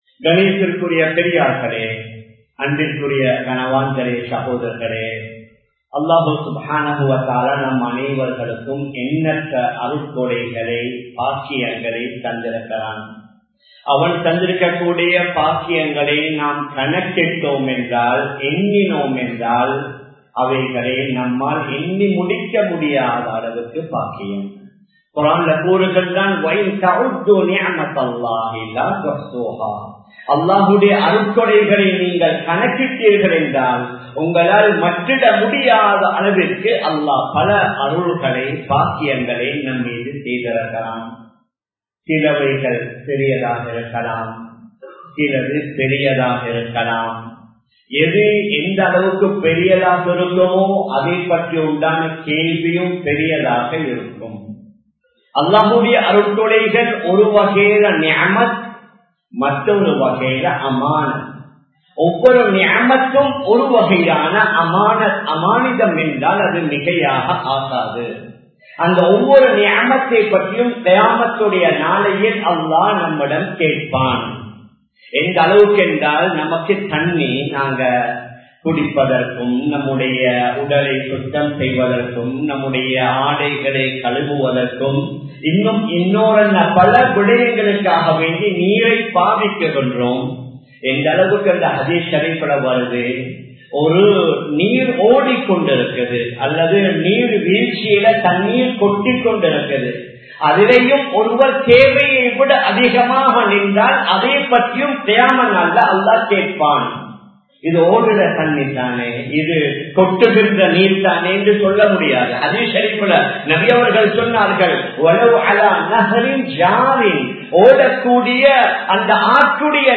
குழந்தைப் பாக்கியம் | Audio Bayans | All Ceylon Muslim Youth Community | Addalaichenai
Samman Kottu Jumua Masjith (Red Masjith)